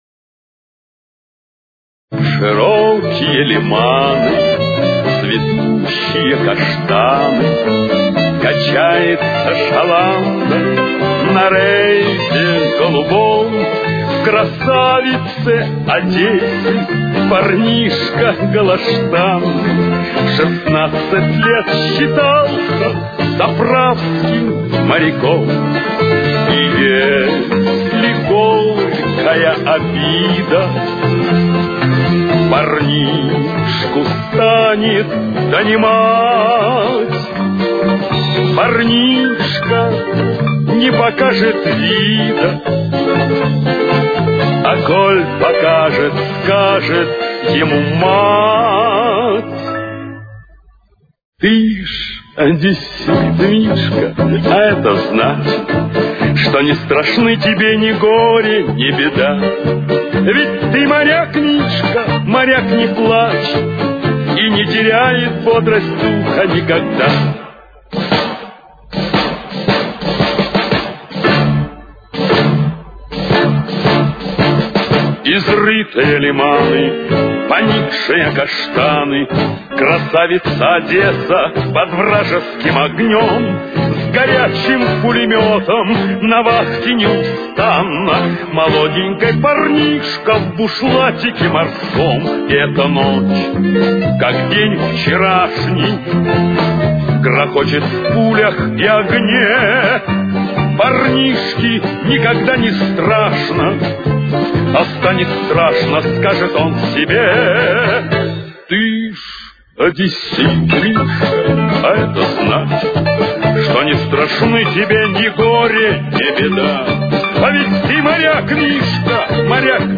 с очень низким качеством (16 – 32 кБит/с)